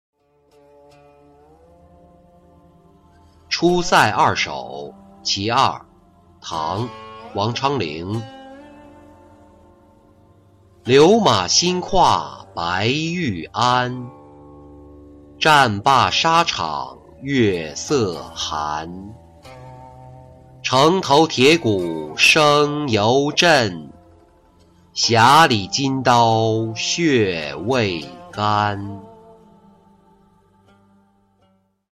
出塞二首·其二-音频朗读